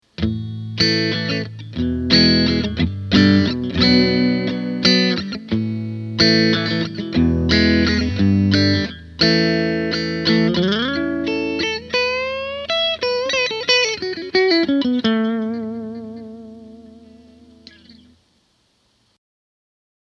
In any case, here are six versions of the same phrase with each different configuration: